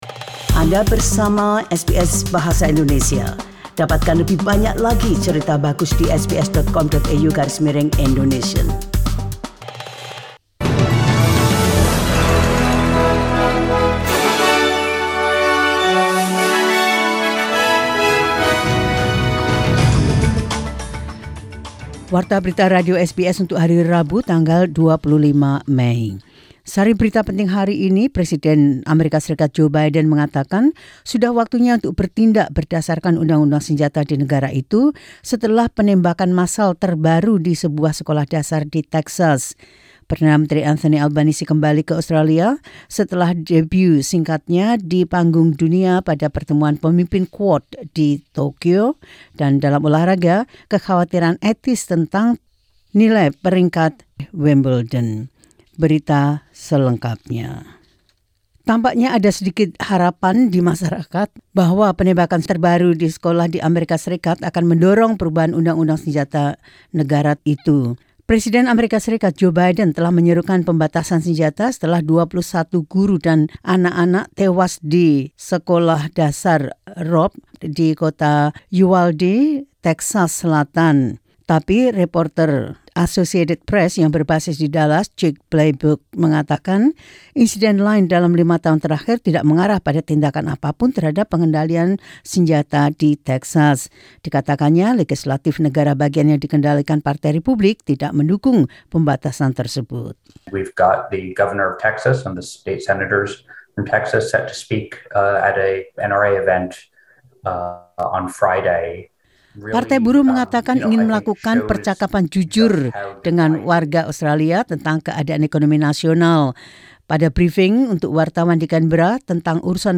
SBS Radio news in Indonesian Source: SBS